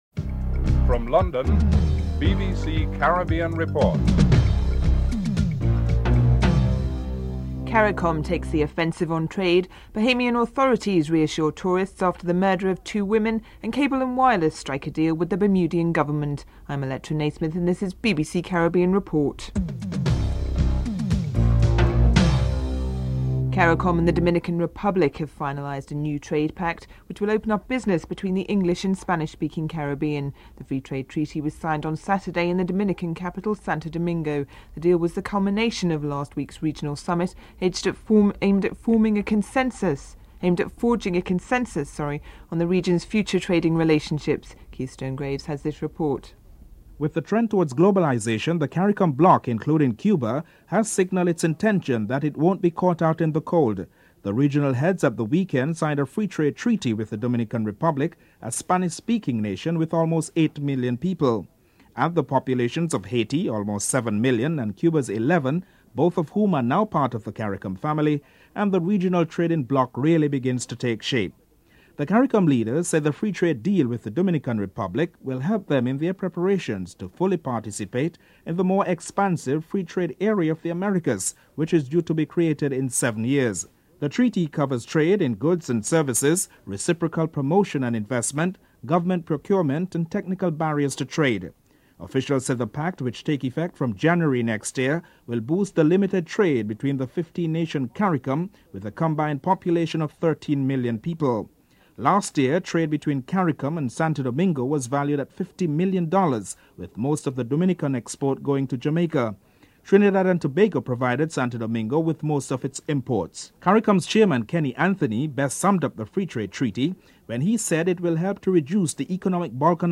Headlines (00:00-00:22)